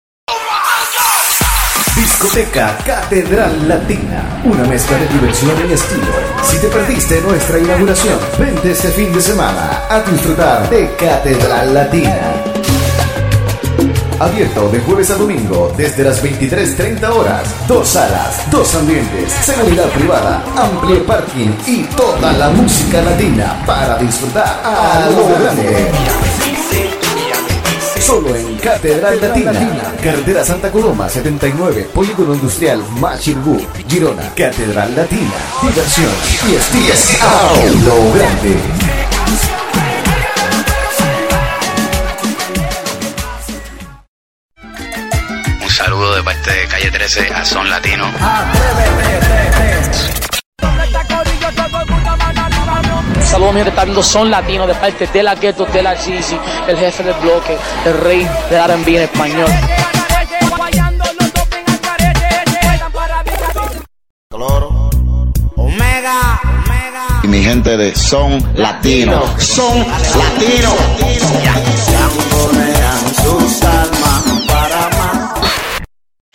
Publicitat, salutació del grup La Calle 13 i d'altres artistes a l'emissora